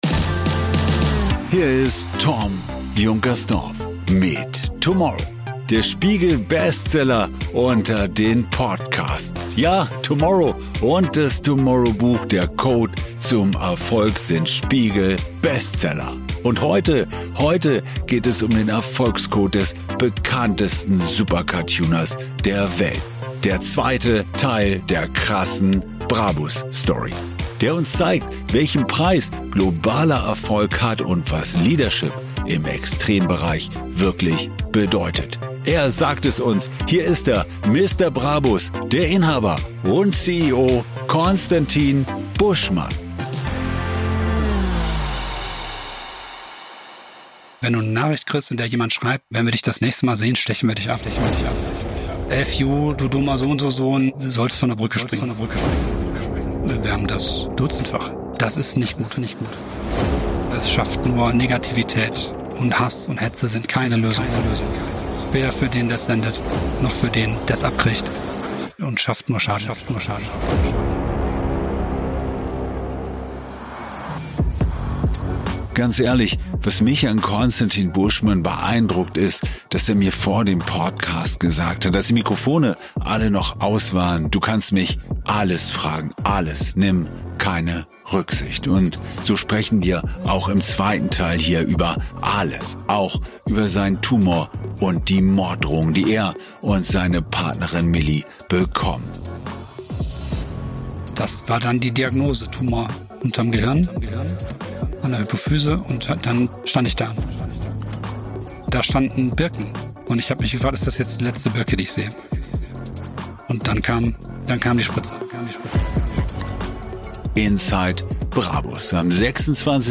Wir haben wir uns im Roomers Hotel in München getroffen.